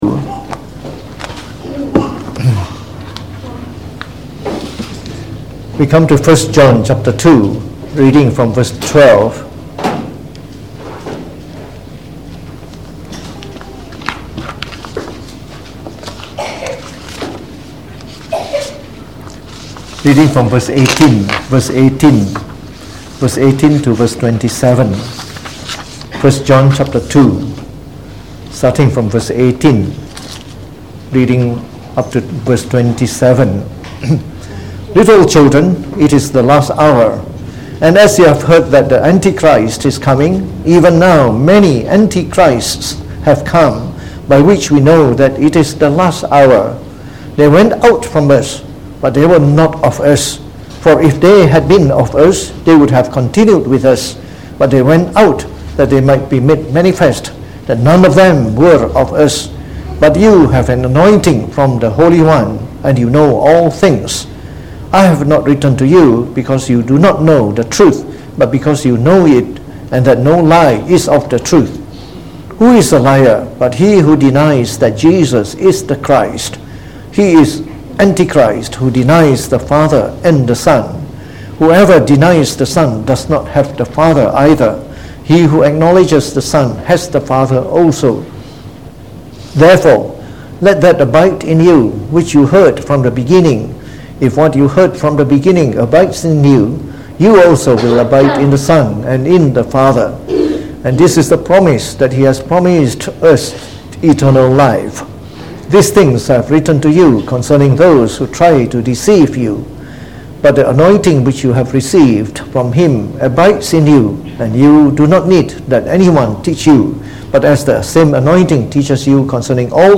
Preached on the 6th of Oct 2019.
delivered in the Evening Service